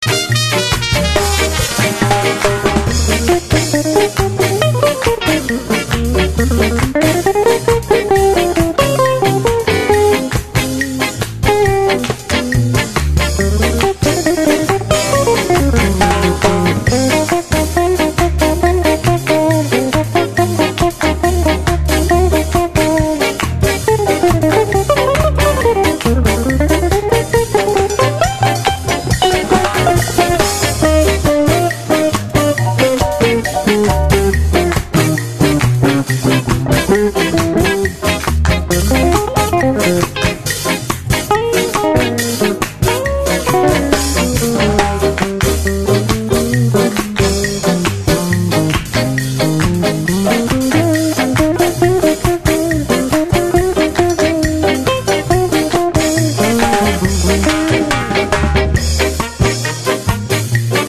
Gypsy Jazz licks: not just for Gypsy Jazz!